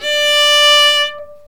Index of /90_sSampleCDs/Roland L-CD702/VOL-1/STR_Viola Solo/STR_Vla3 _ marc
STR VIOLA 0D.wav